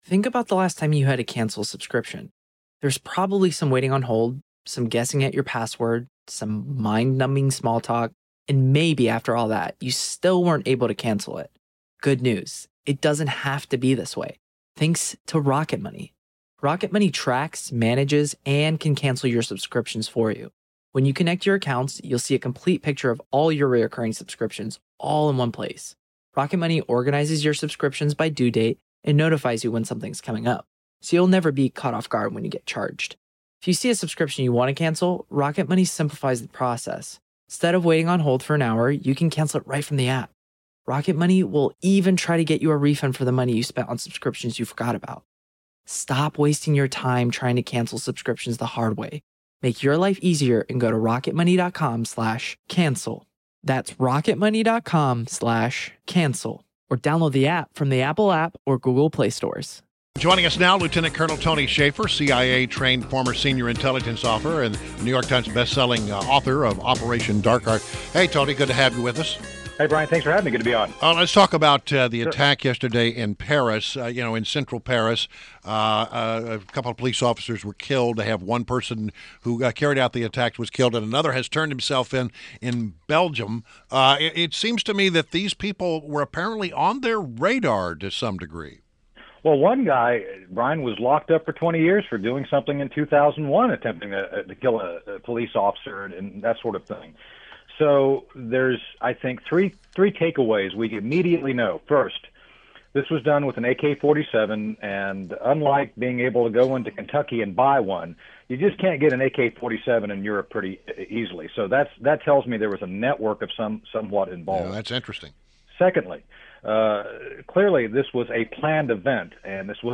WMAL Interview - LT COL TONY SHAFFER - 04.21.17